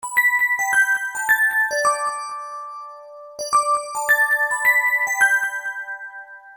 ドミソド（ピンポンパンポ〜ン♪）
チャイム（アラーム音用）
再生する機種により、音は、多少異なって聞こえます。